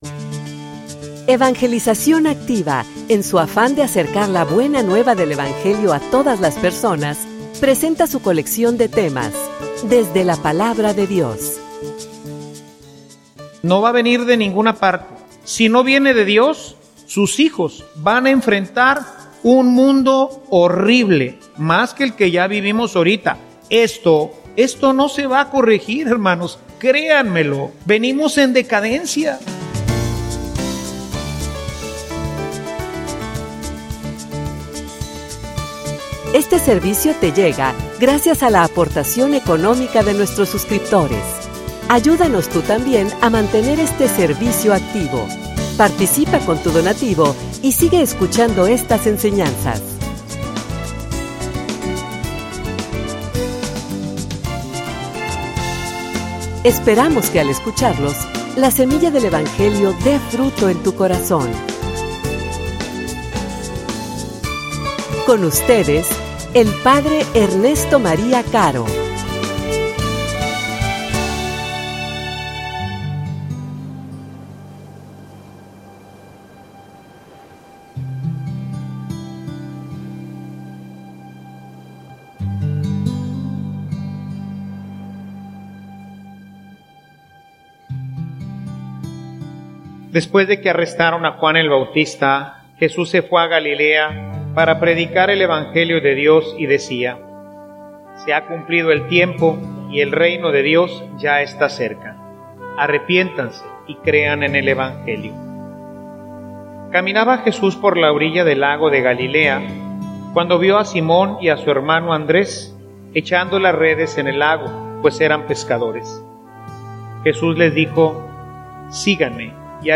homilia_Sin_evangelio_no_hay_futuro.mp3